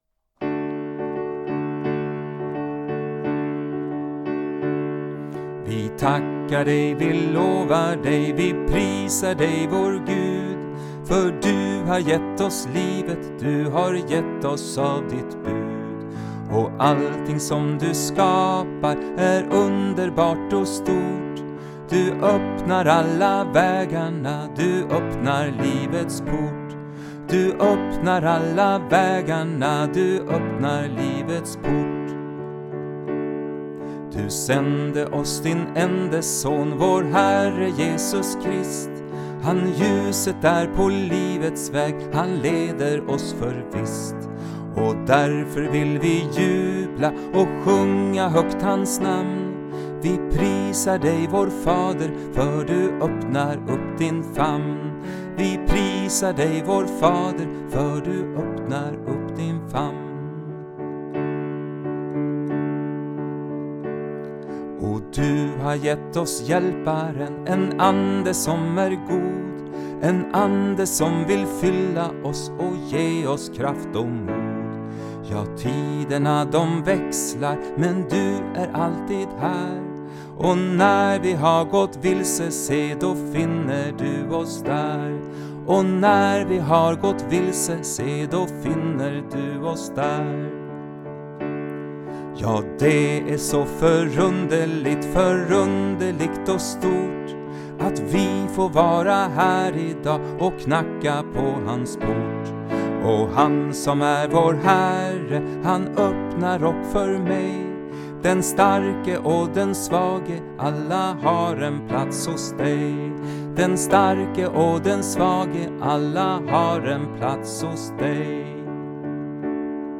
En psalm